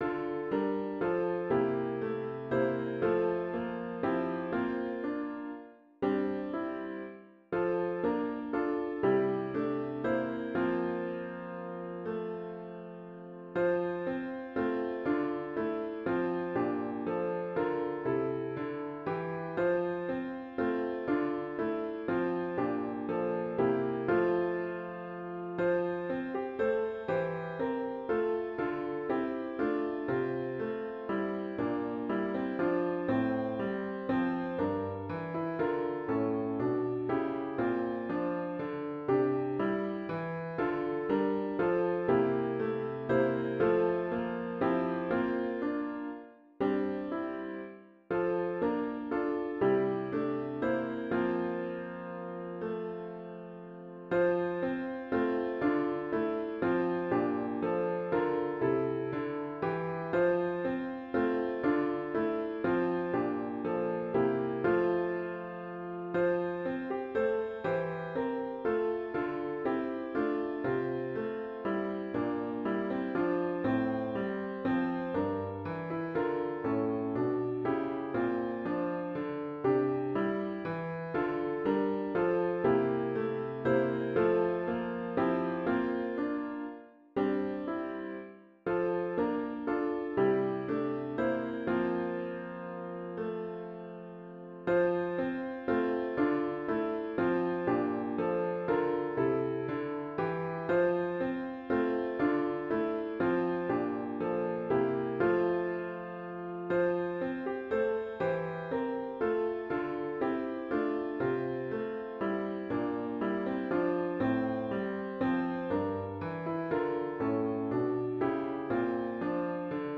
Sunday December 22, 2024 Worship Service
*HYMN “Love Has Come” GtG 110